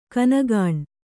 ♪ kanagāṇ